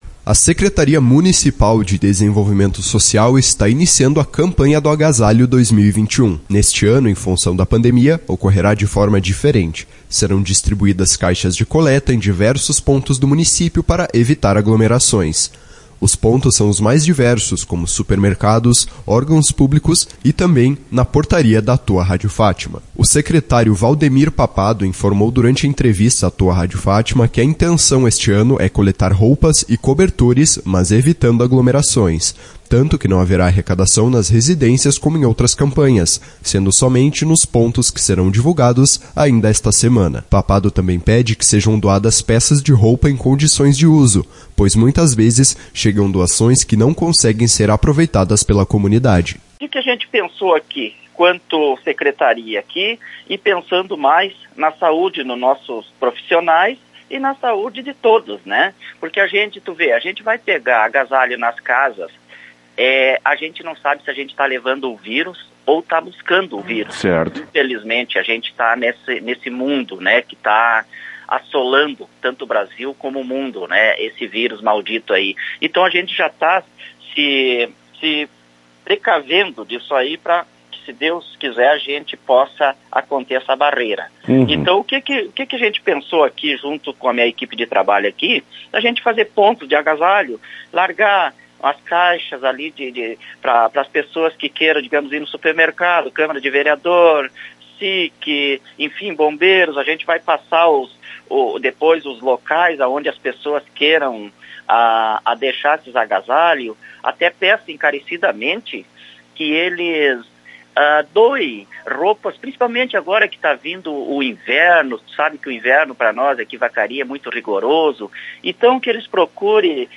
O secretário Valdemir Papado, informou durante entrevista à Tua Rádio Fátima, que a intenção este ano é coletar roupas e cobertores, mas evitando aglomerações.